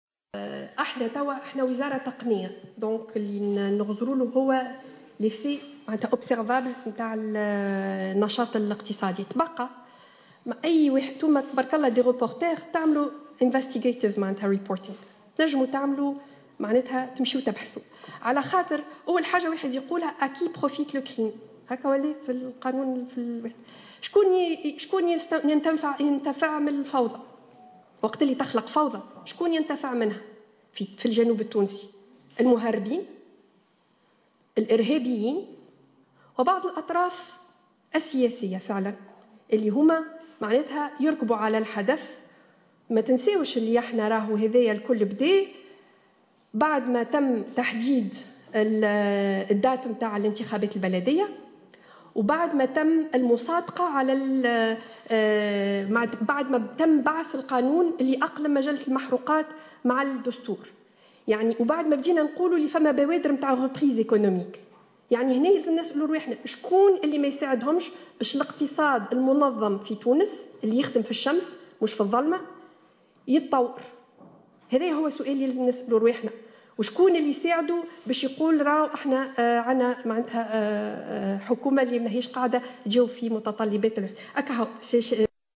وأضافت خلال ندوة صحفيّة انعقدت اليوم بقصر الحكومة بالقصبة أن هناك من لا يرغب في تحديد موعد لإجراء الإنتخابات البلديّة والمصادقة على مجلّة الاستثمار الجديدة وكذلك إرساء اقتصاد منظم.